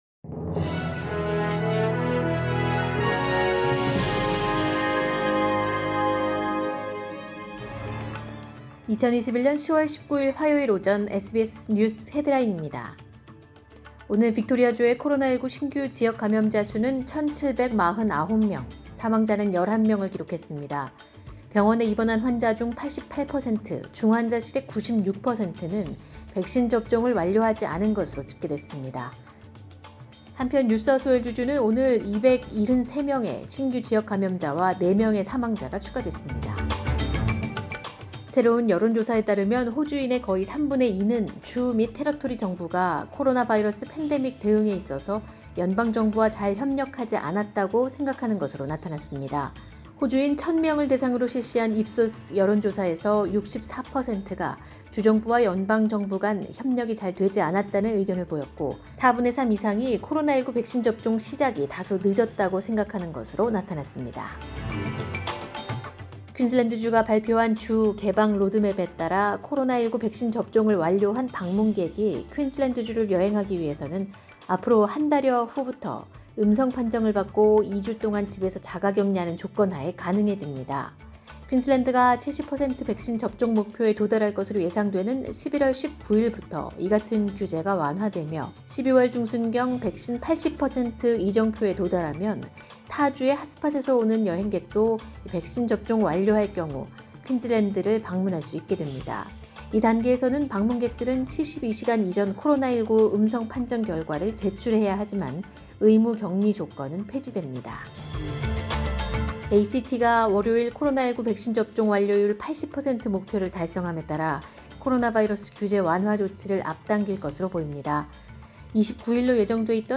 2021년 10월 19일 화요일 오전의 SBS 뉴스 헤드라인입니다.